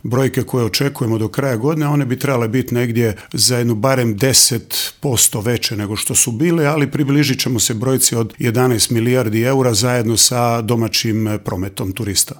Kako zadržati rast i iduće godine, produljiti sezonu, ali i rješiti problem održivosti turizma te kroničnog nedostatka radne snage u intervjuu Media servisu otkriva resorni ministar Gari Cappelli.